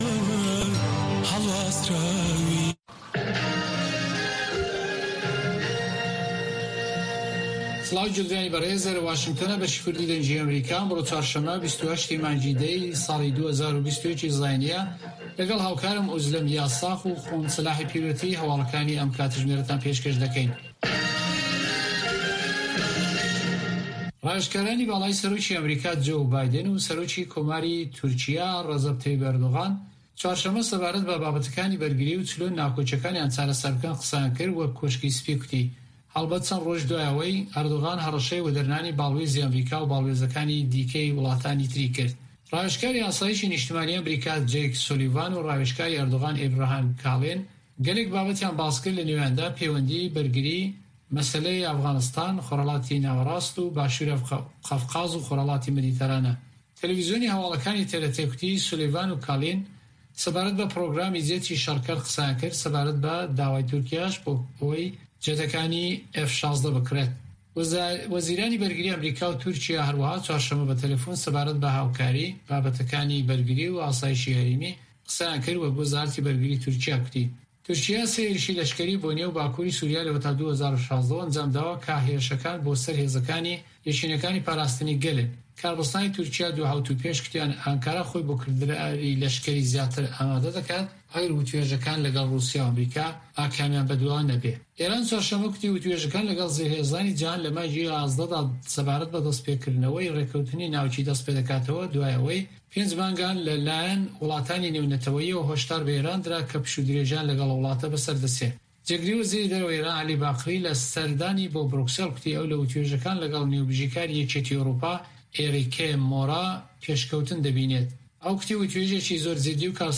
Nûçeyên 1’ê şevê
Nûçeyên Cîhanê ji Dengê Amerîka